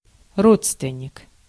Ääntäminen
France: IPA: /pa.ʁɑ̃/